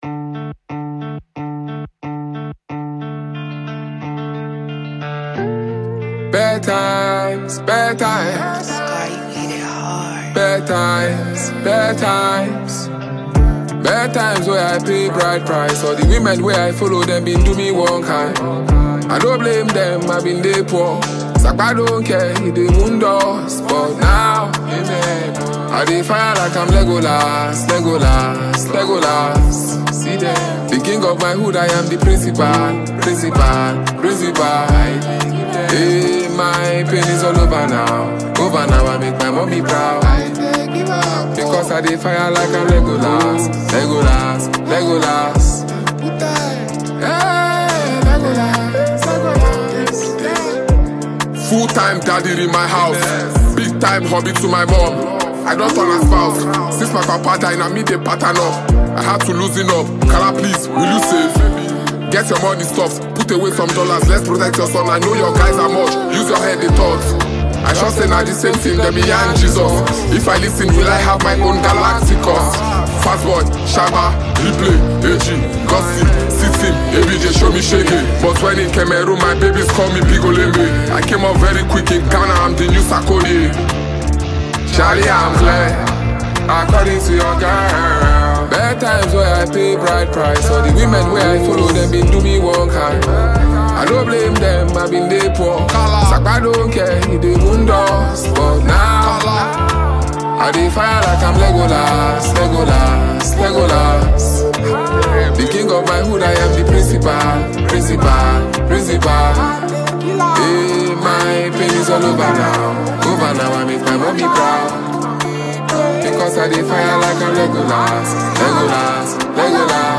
a multifaceted Nigerian rap sensation and lyricist